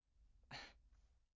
SFX文件夹一 " 坐起来 呼吸变得迟钝
描述：坐从长沙发的男性 呼气
Tag: 平淡 呼气 公寓 呼吸急促 坐起 沙发 呼吸